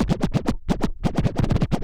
scratch14.wav